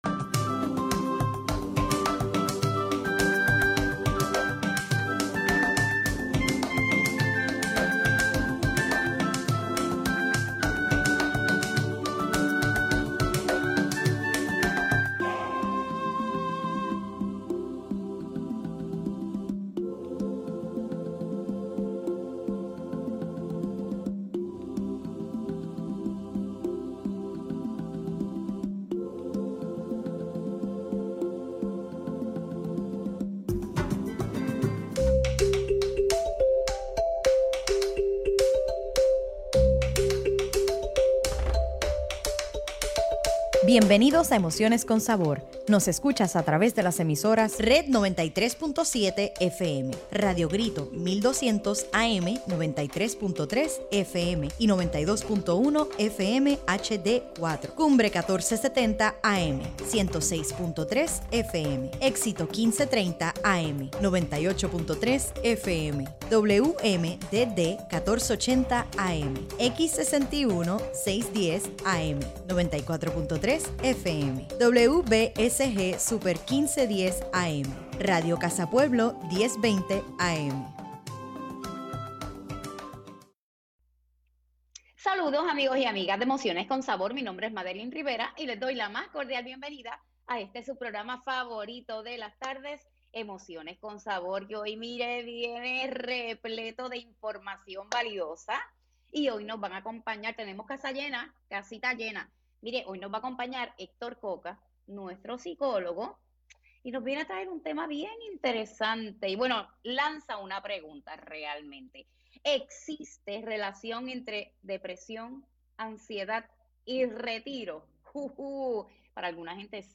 ¡El verano se siente también en la radio!